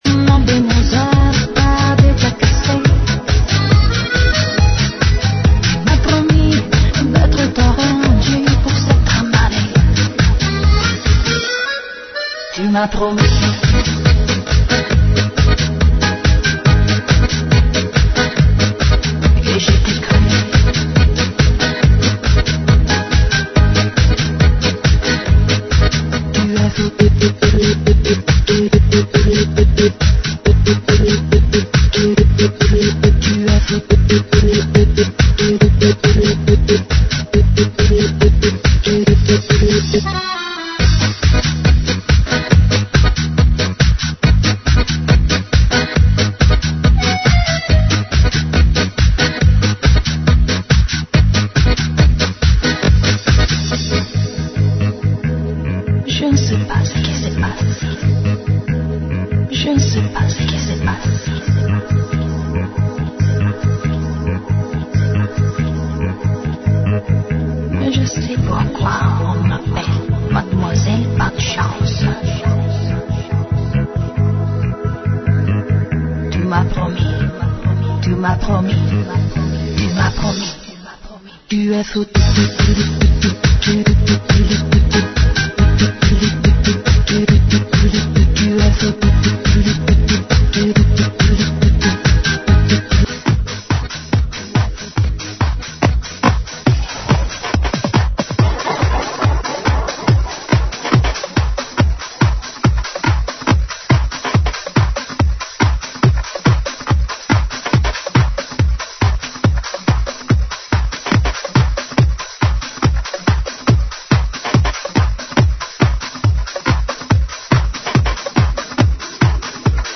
GENERO: POP – INGLES – DANCE